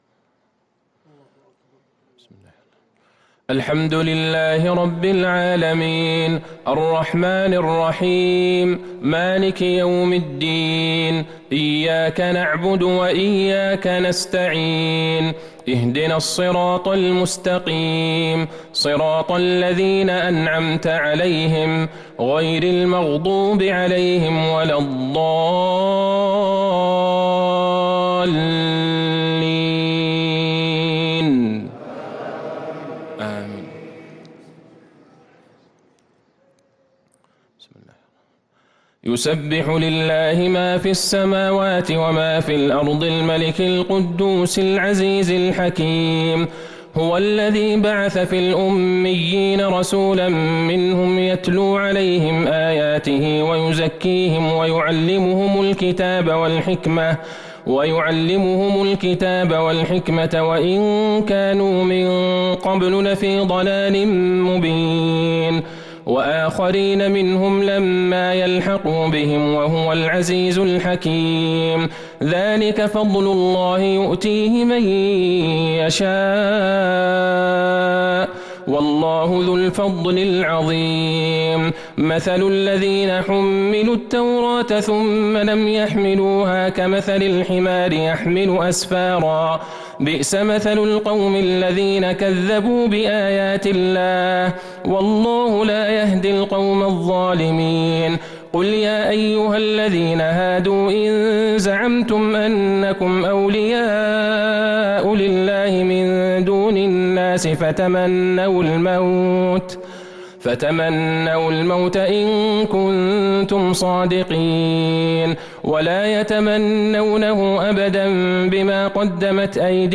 صلاة الجمعة ١٥ شعبان ١٤٤٣هـ | سورتي الجمعة و المنافقون | Jumu’ah prayer from Surah Al-Jumu’ah & Al-Monafiqon9-3-2022 > 1443 🕌 > الفروض - تلاوات الحرمين